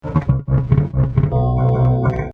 stick 2 2 sec. stereo 57k
stick2.mp3